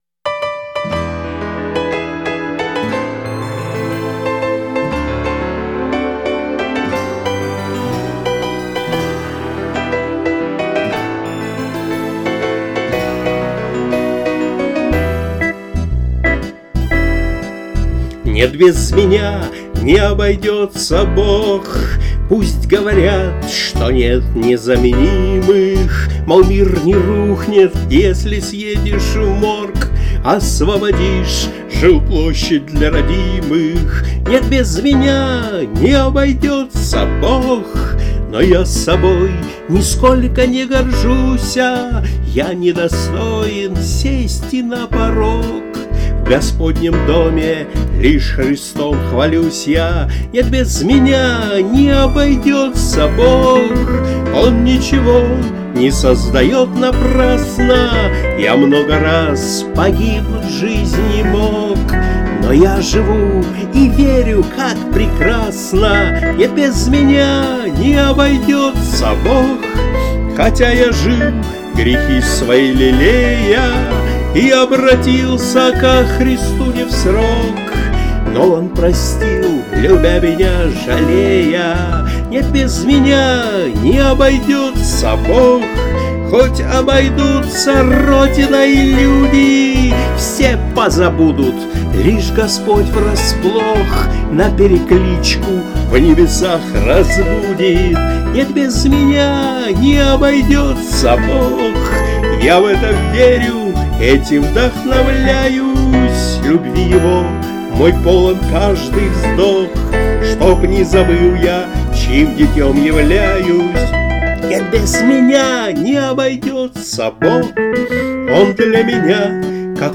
Христианские музыкальные песни